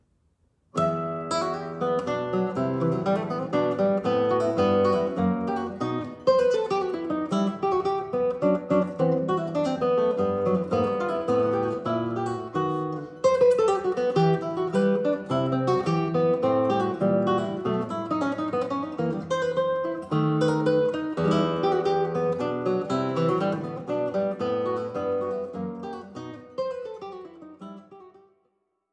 für Gitarre
guitar